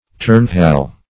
Search Result for " turnhalle" : The Collaborative International Dictionary of English v.0.48: Turnhalle \Turn"hal`le\, n. [G., from turnen to exercise gymnastics + halle hall.]
turnhalle.mp3